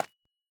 Minecraft Version Minecraft Version 21w07a Latest Release | Latest Snapshot 21w07a / assets / minecraft / sounds / block / calcite / place4.ogg Compare With Compare With Latest Release | Latest Snapshot